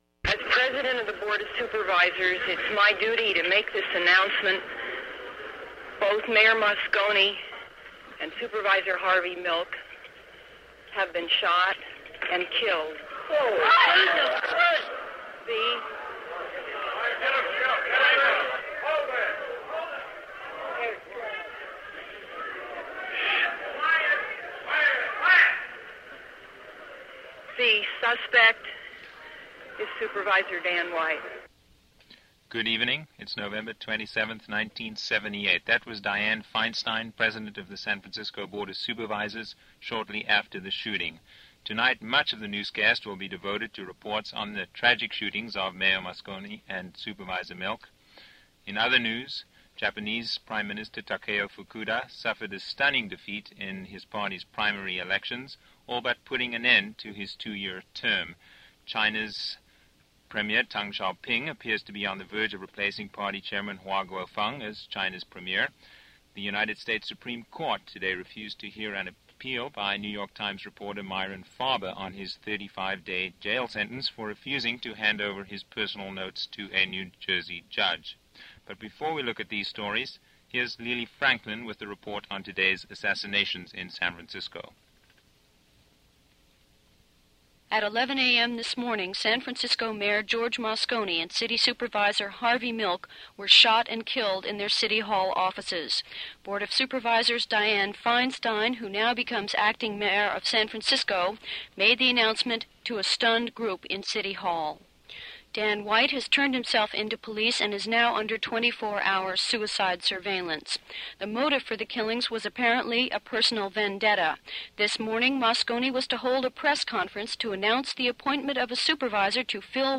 – KPFA Evening News – Special Report – November 27, 1978 – KPFA/KPFB – Pacifica Network –